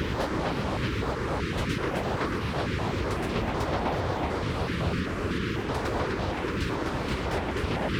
Index of /musicradar/stereo-toolkit-samples/Tempo Loops/120bpm
STK_MovingNoiseF-120_01.wav